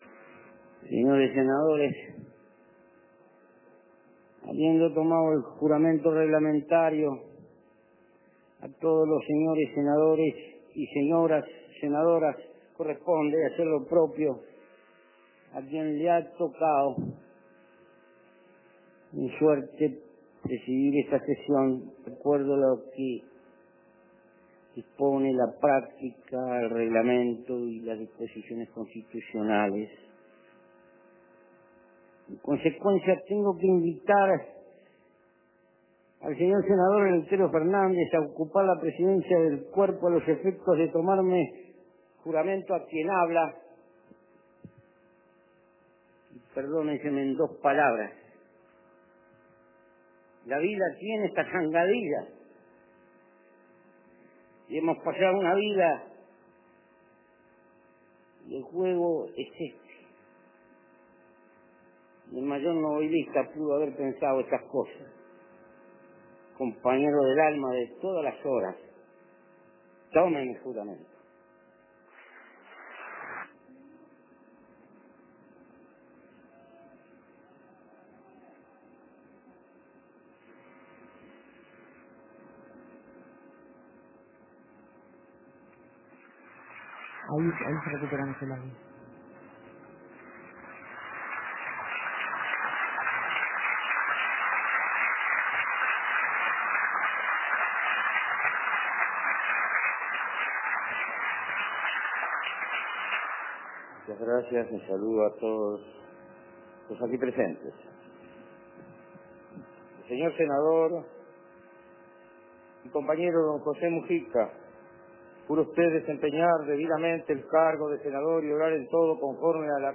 Escuche el momento en que Eleuterio Fernández Huidobro le toma el juramente a José Mujica.